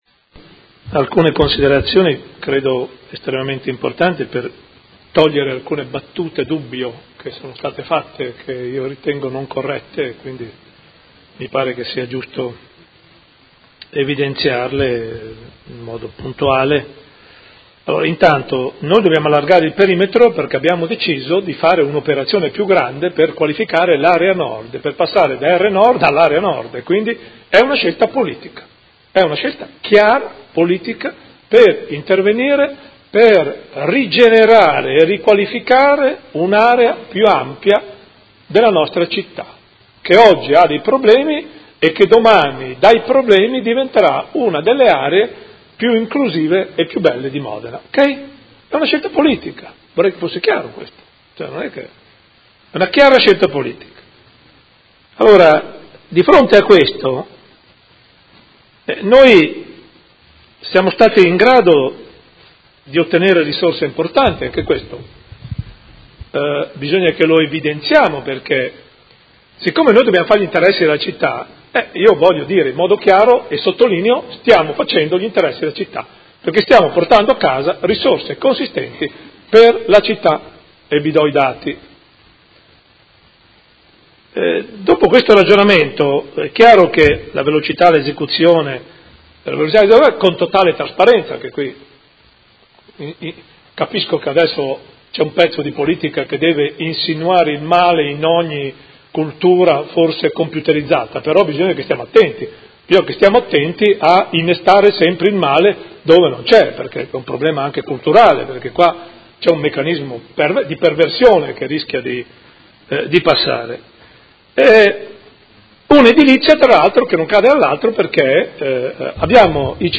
Seduta del 23/11/2017 Conclusioni su dibattito.